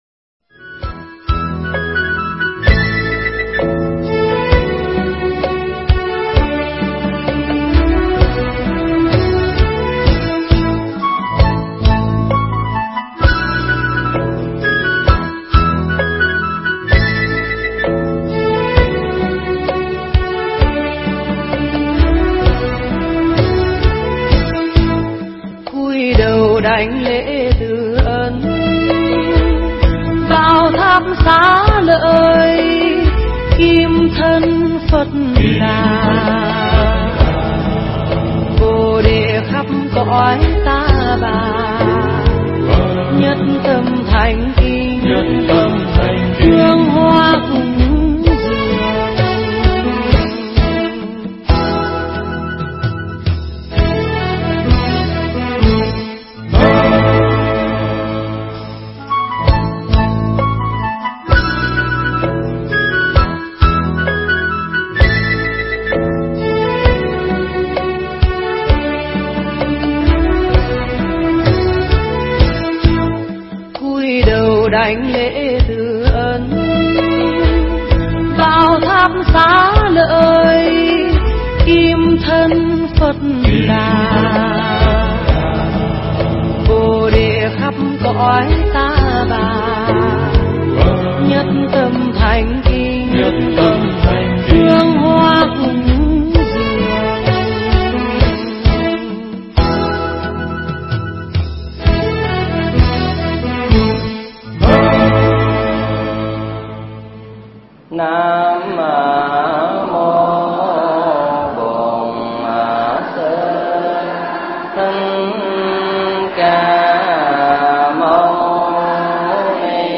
Thuyết pháp
tại chùa Ấn Quang (Quận 10, TP. HCM)